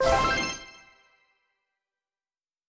Special & Powerup (41).wav